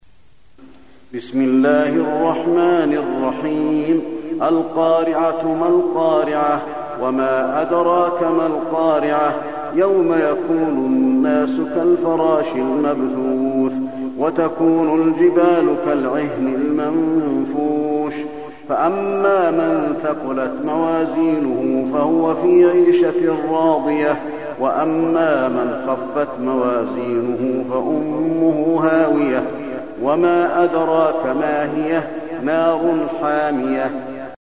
المكان: المسجد النبوي القارعة